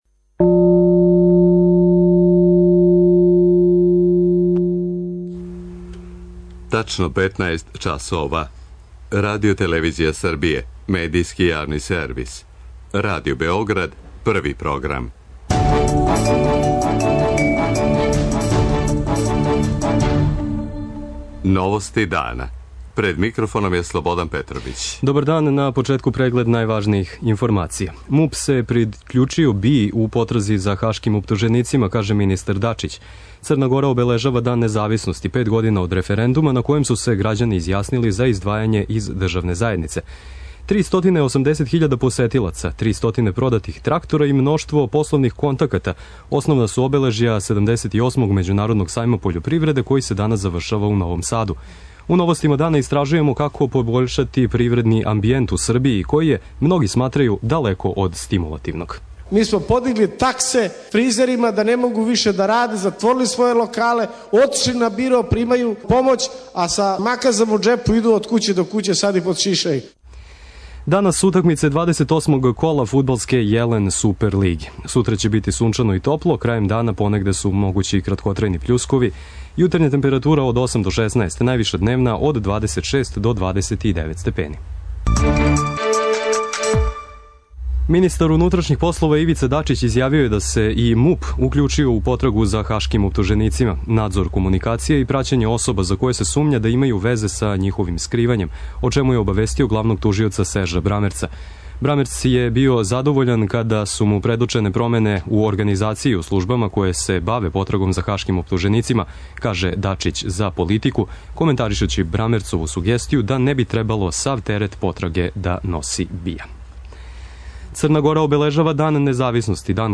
О овој и другим темама дана слушајте у главној информативној емисији радија Београд 1, коју свакога дана можете пронаћи у мп3 формату на овој адреси.